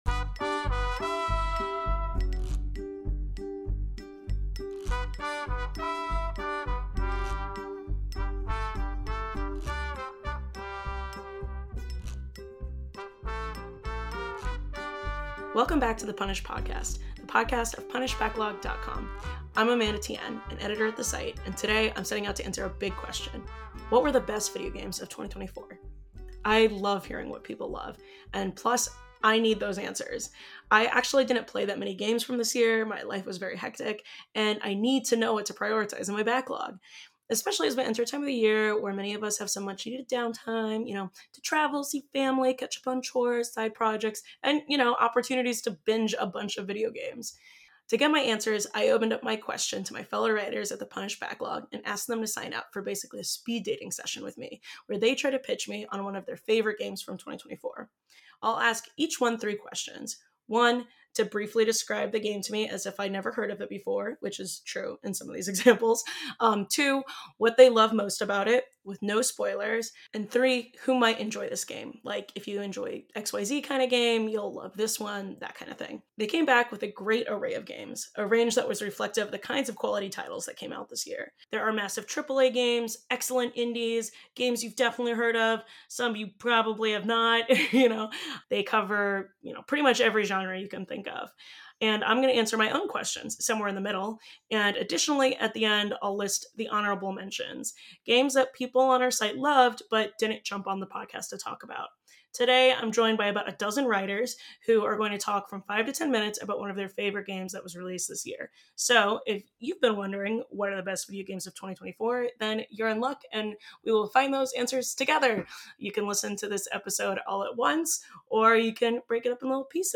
This episode features about a dozen writers (myself included!) and their picks. Each mini-interview is about five to 10 minutes long. In each, I ask for a description of the game, what the writer loved most about it, and who else might enjoy it.